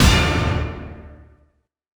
orchestra chord.ogg